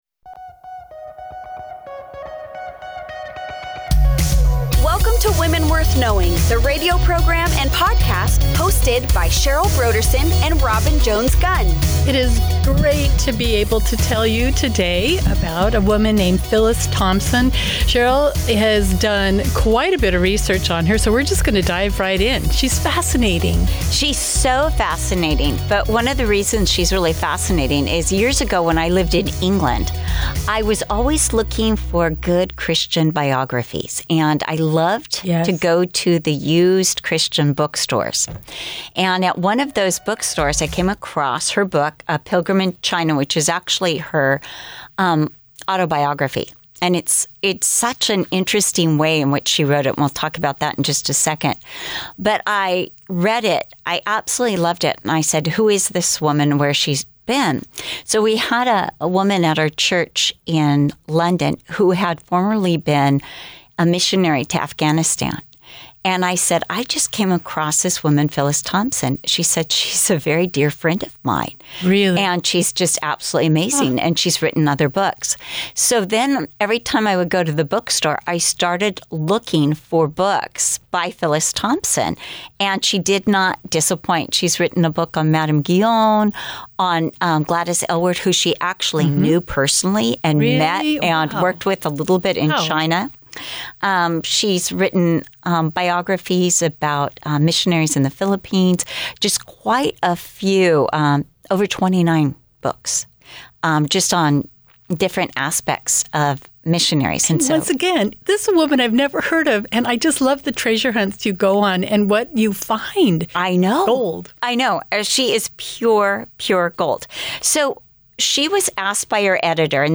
Join us each week for a lively conversation